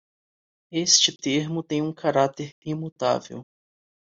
Pronounced as (IPA)
/kaˈɾa.teʁ/